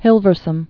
(hĭlvər-səm)